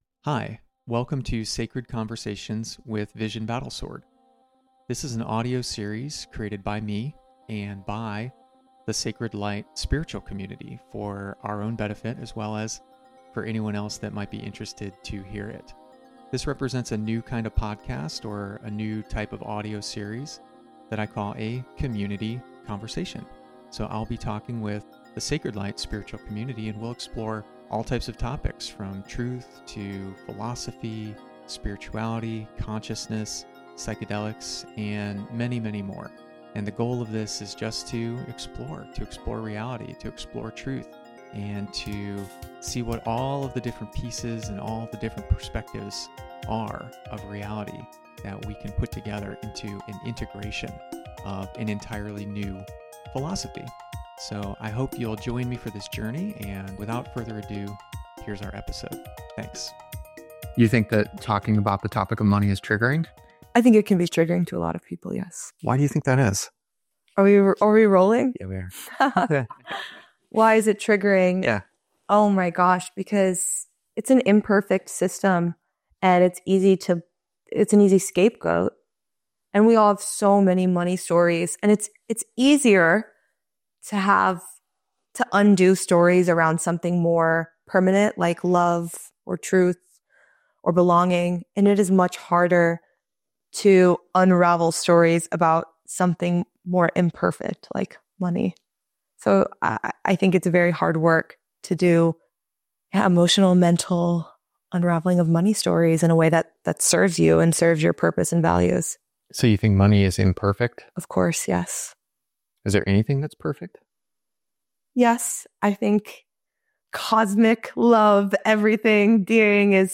conversation34-money.mp3